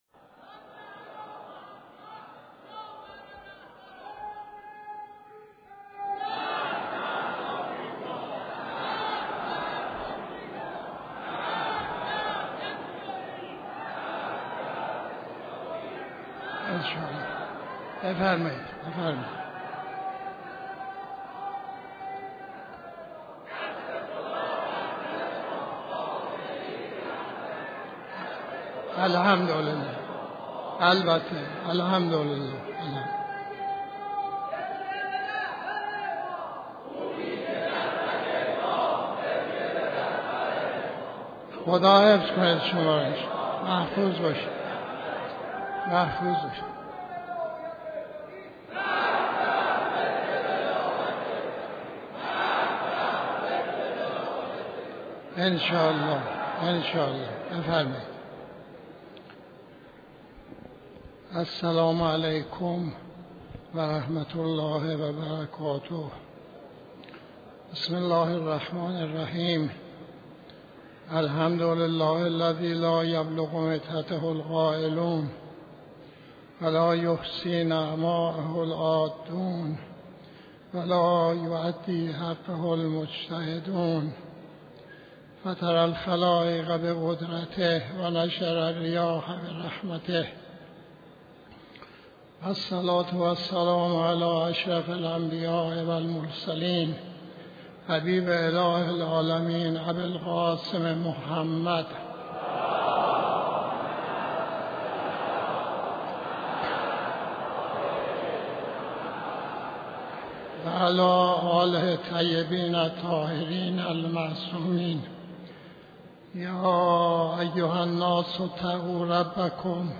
خطبه نماز جمعه 02-04-91